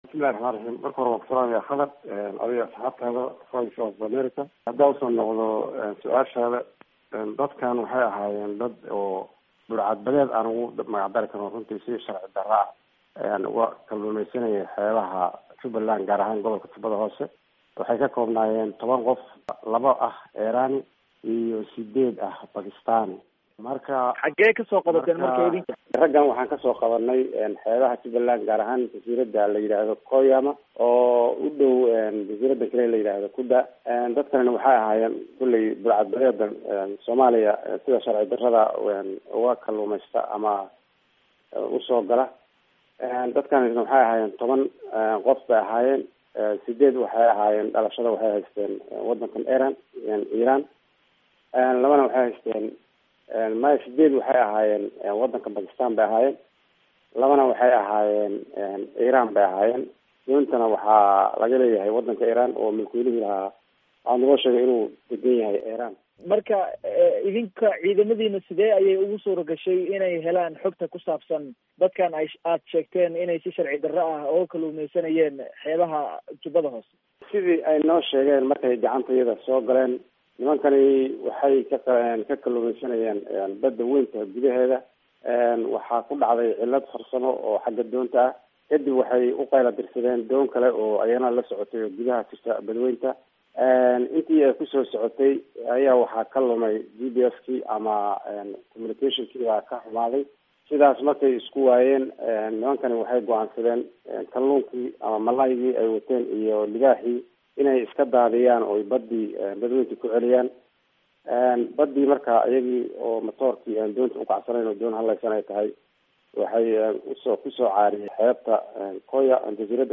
Wareysi: Guddoomiyaha Jubbada Hoose